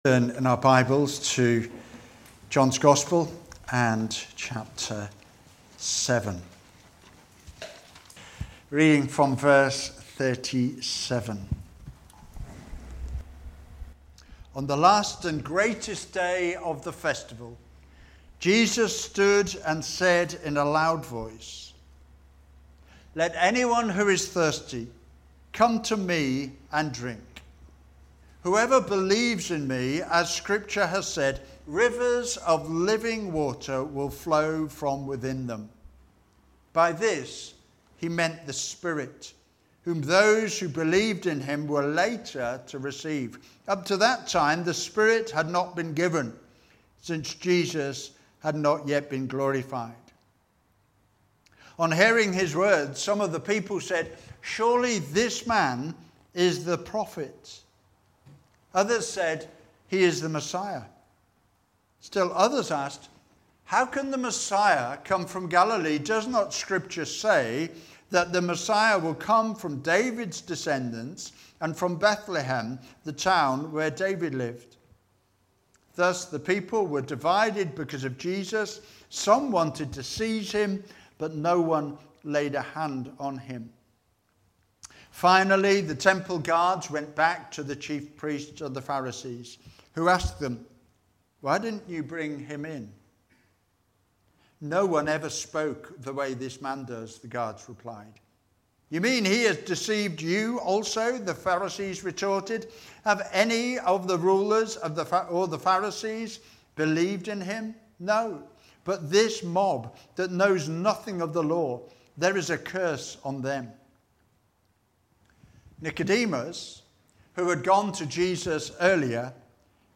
A New Heart Preacher